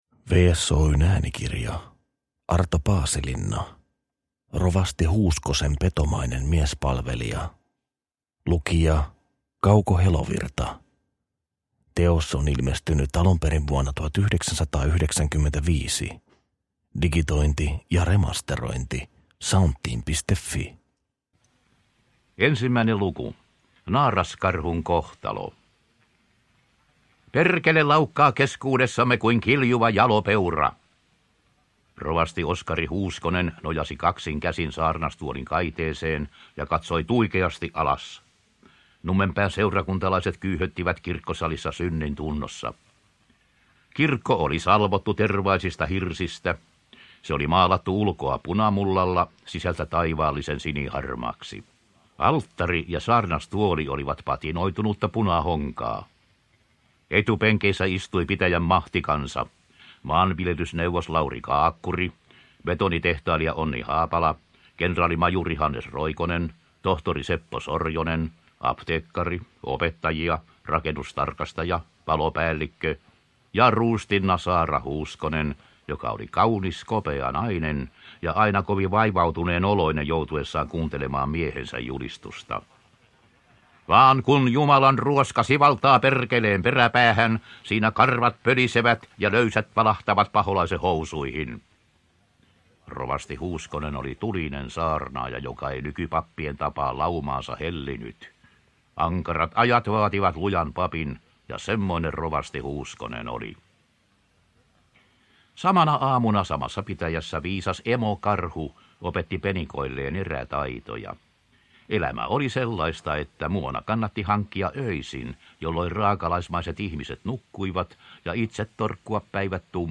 Rovasti Huuskosen petomainen miespalvelija – Ljudbok